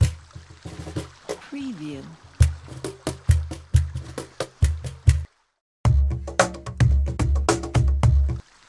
مجموعه ریتم و لوپ کاخن
demo-cajon.mp3